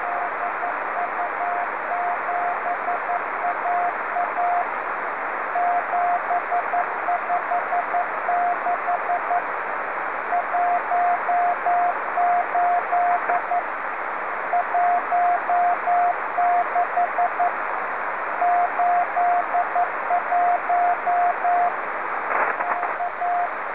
7021,85 y 14043,70 KHz CW
Audio recibido en 40 metros el 14ago2012 10:12 hs LU, se escucha con algo de ruido, pero con filtro angosto sale mejor.